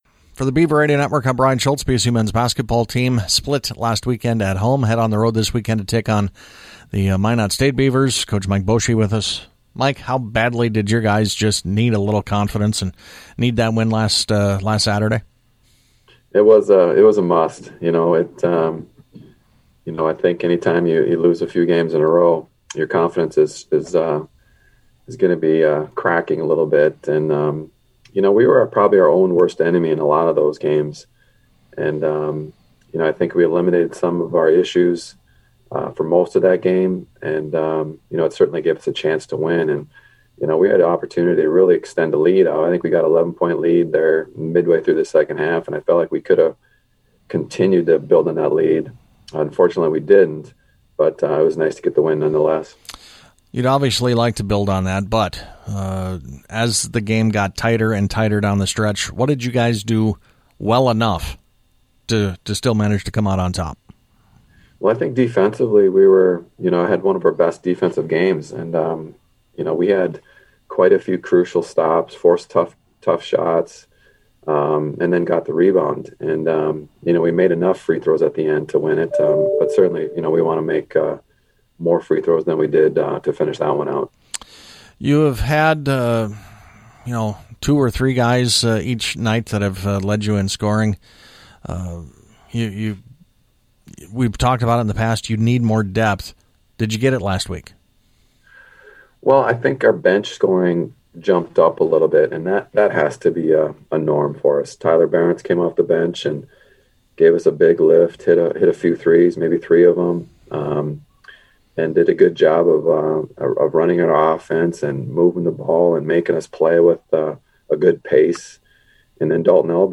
Halftime Interview